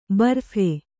♪ barphe